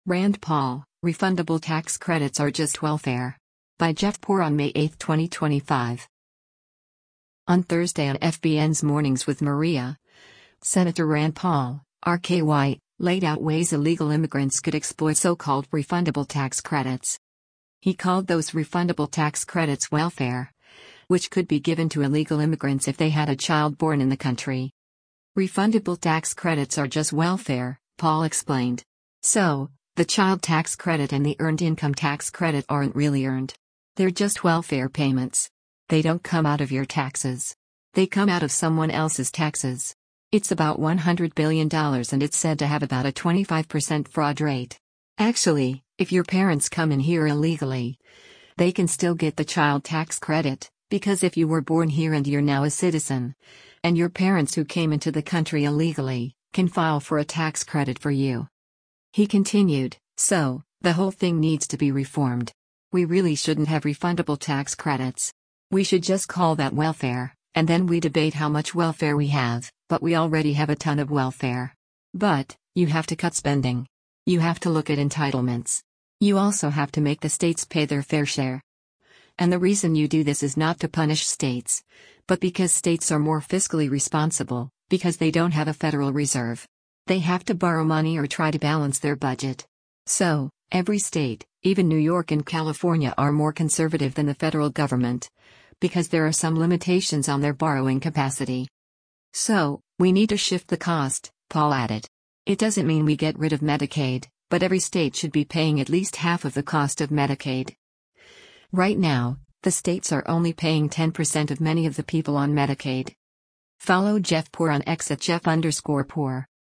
On Thursday on FBN’s “Mornings with Maria,” Sen. Rand Paul (R-KY) laid out ways illegal immigrants could exploit so-called refundable tax credits.